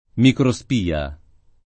[ mikro S p & a ]